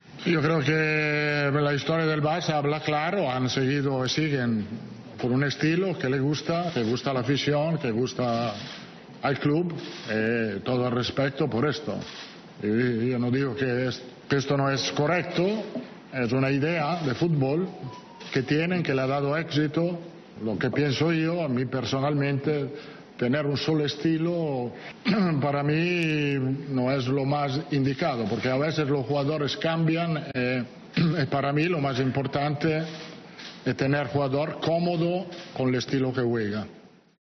Real Madrid | Rueda de prensa